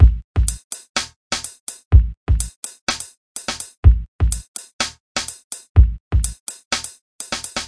beat with snare 4 4 125bpm blobby type kick fizzy hats " beat with snare 4 4 125bpm blobby type kick fizzy hats
描述：用圈套击败4 4 125bpm blobby型踢嘶嘶的帽子